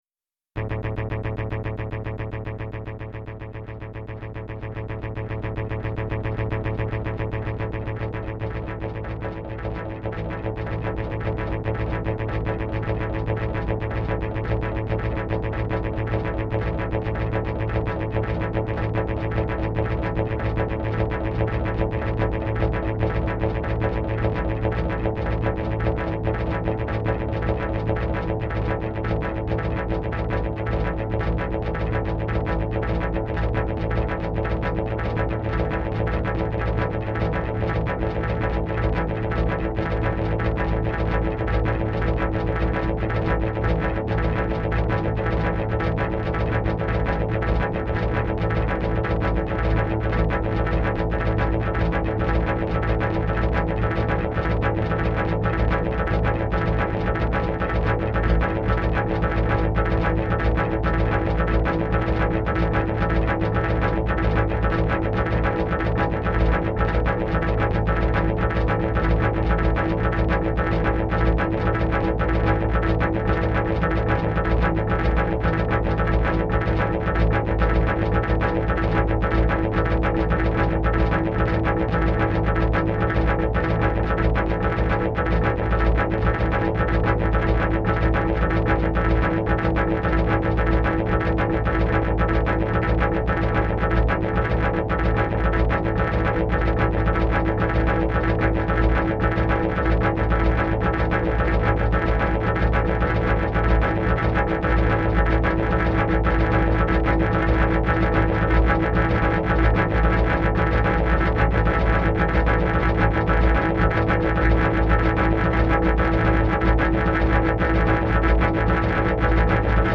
Drum_Obsession_2.mp3